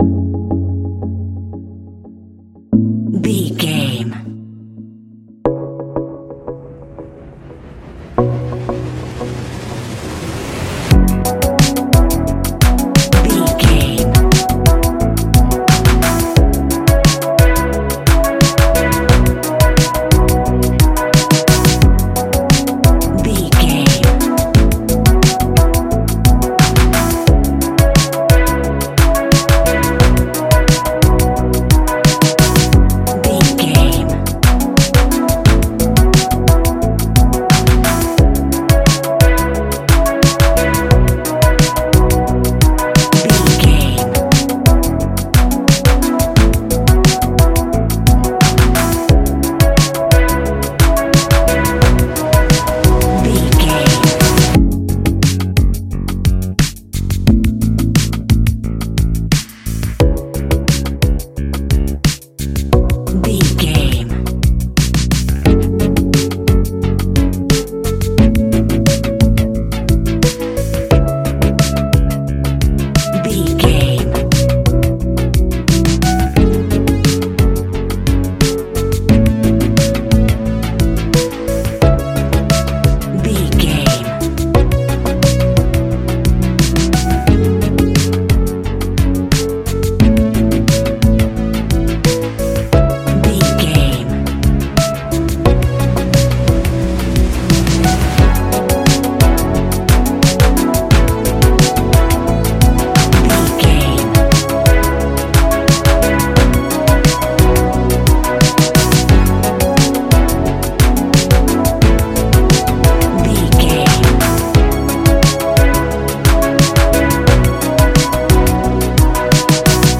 Ionian/Major
F♯
ambient
electronic
new age
downtempo
synth
pads